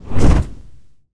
whoosh_magic_spell_01.wav